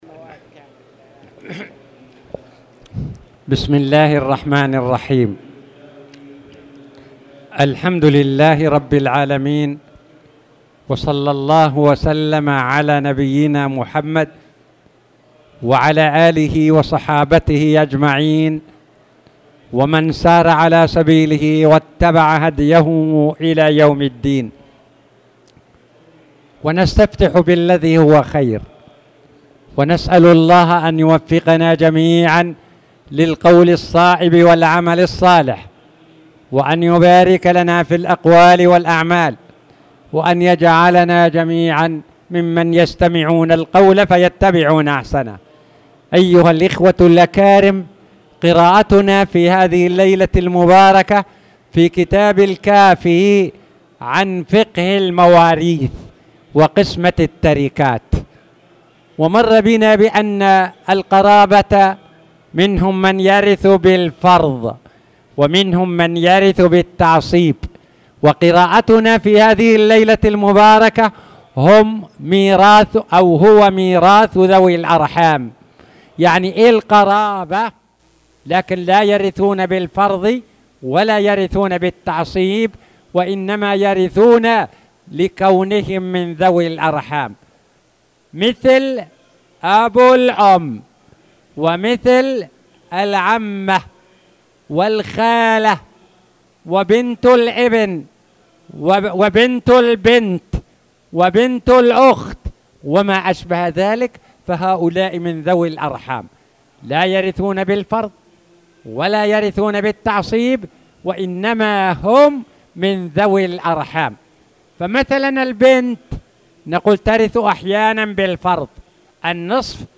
تاريخ النشر ٦ ذو القعدة ١٤٣٧ هـ المكان: المسجد الحرام الشيخ